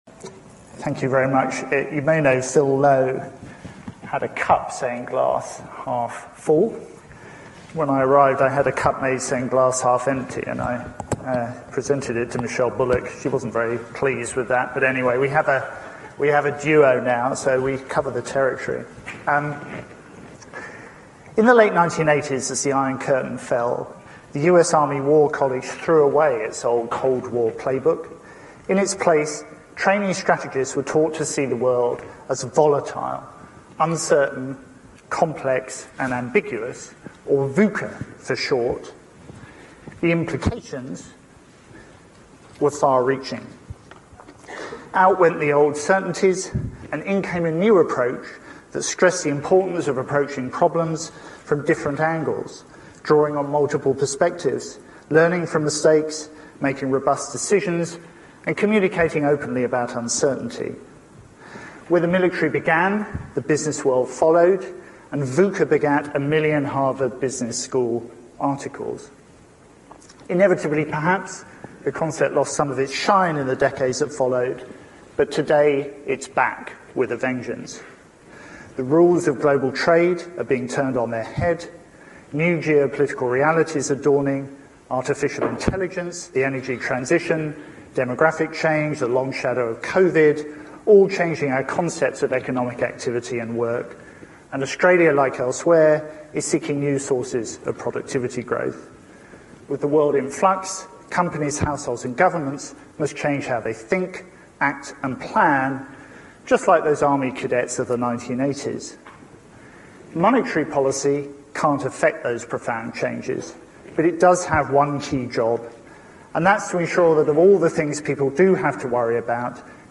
Speech delivered by Andrew Hauser, Deputy Governor, The Australian Financial Review Business Summit, Sydney
The Australian Financial Review Business Summit Sydney – 5 March 2025